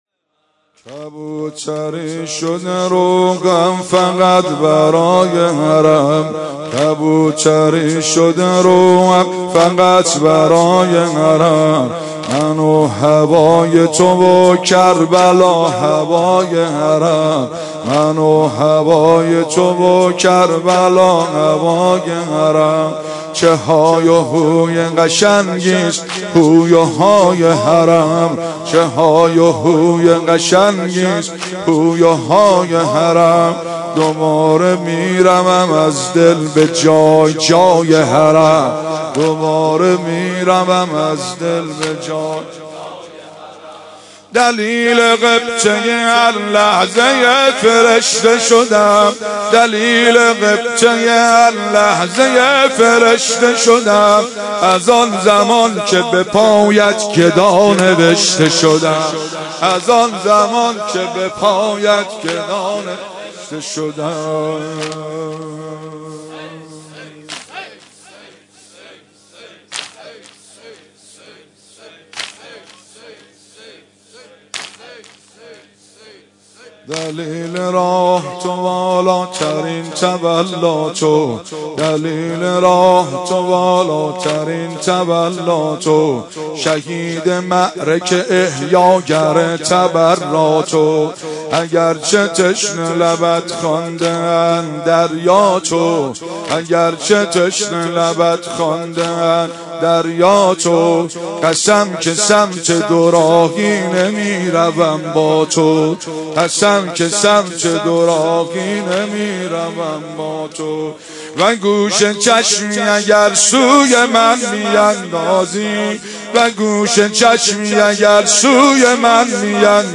مداح
قالب : سنگین